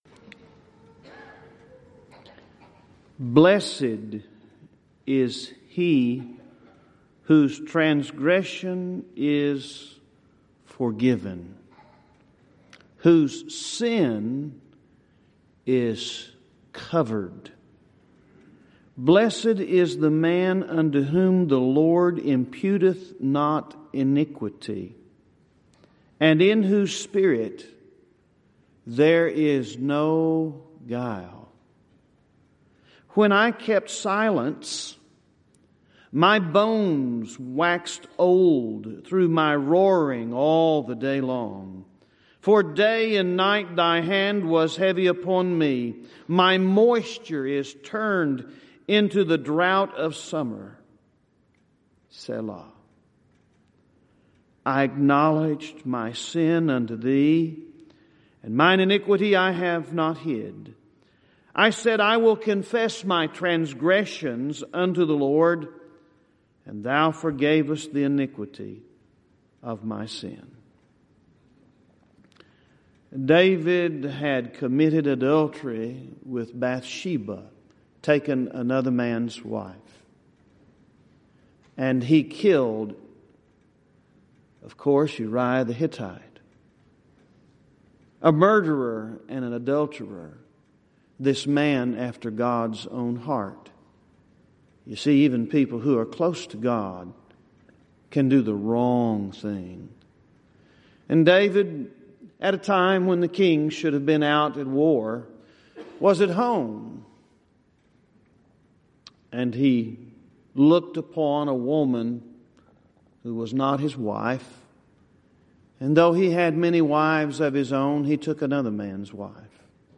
Event: 29th Annual Southwest Lectures
this lecture